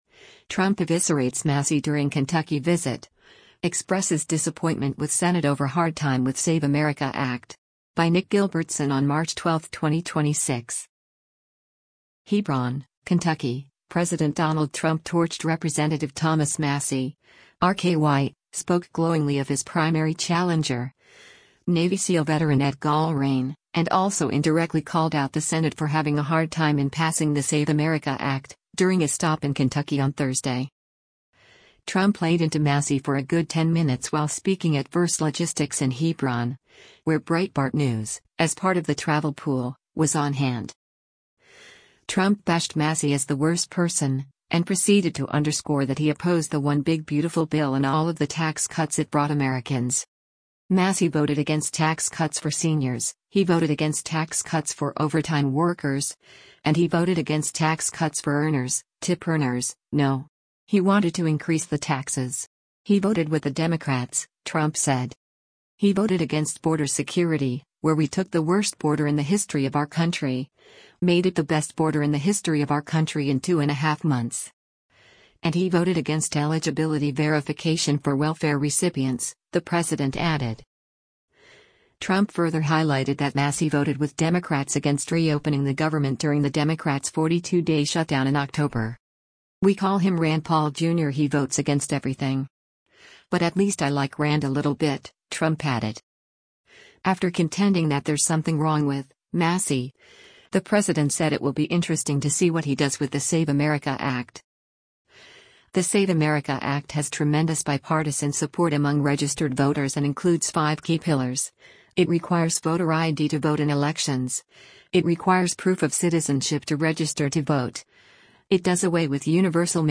U.S. President Donald Trump speaks on stage at Verst Logistics on March 11, 2026 in Hebron